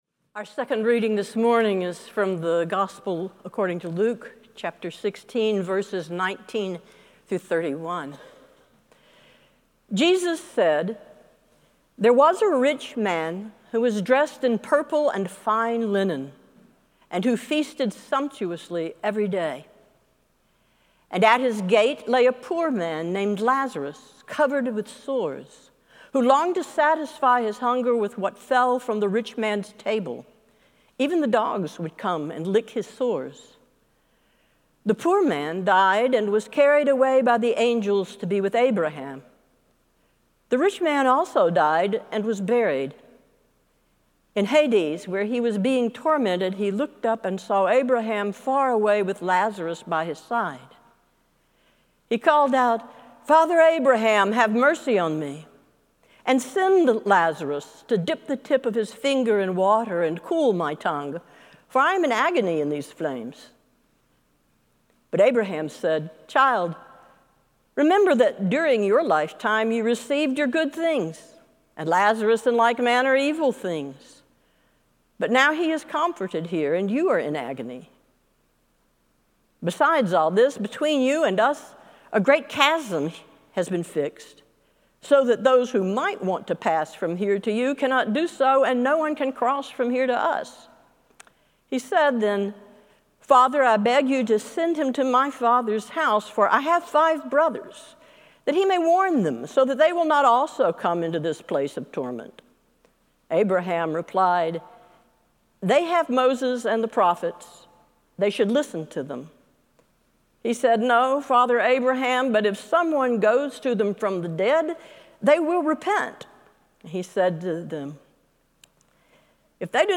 Sermon+9-28-25.mp3